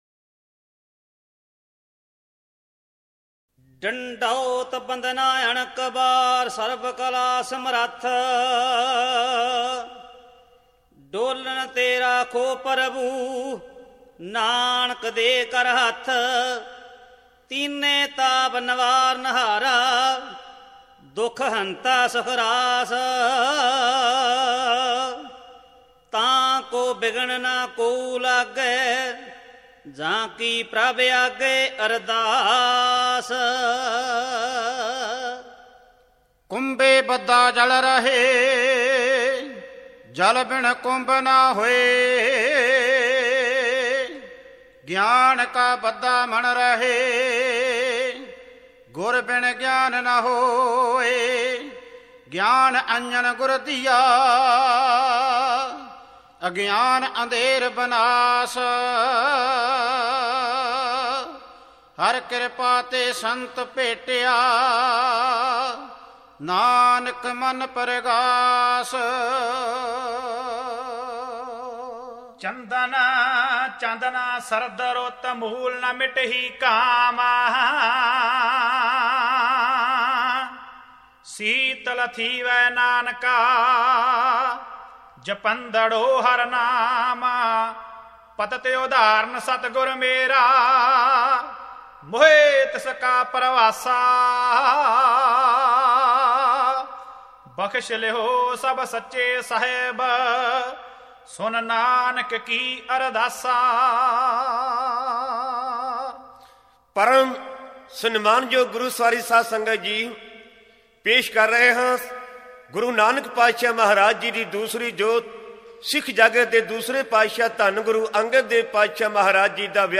Dhadi Vaara